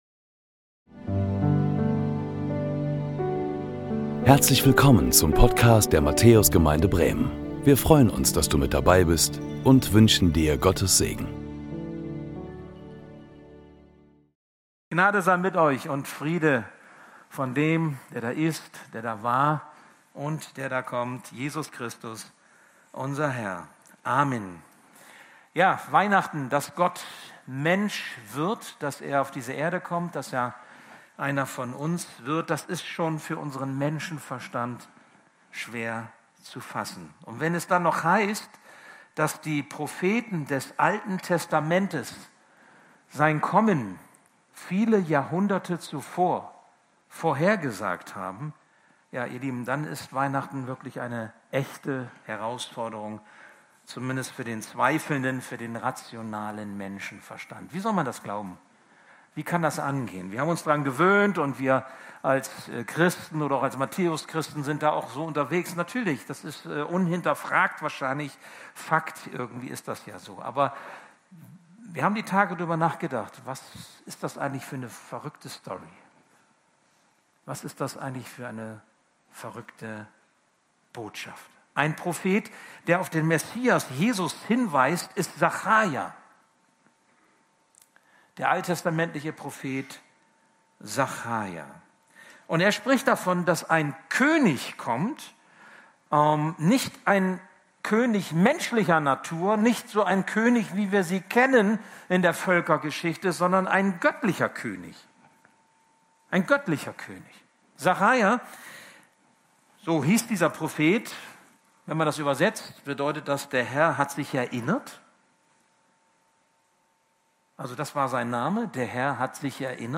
Predigten der Matthäus Gemeinde Bremen Christus ist da!